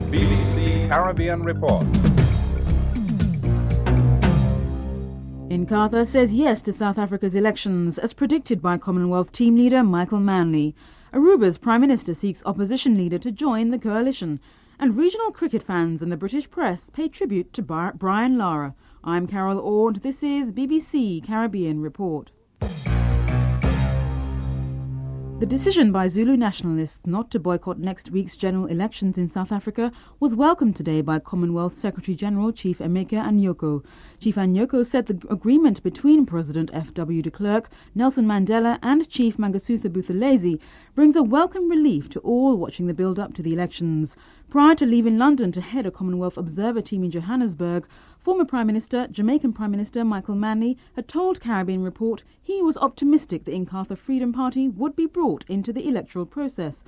Radio stations across the Caribbean were host to men and women who had mixed opinion about Lara’s monumental 375 innings [voices of radio callers across the Caribbean] (09:44-11:46)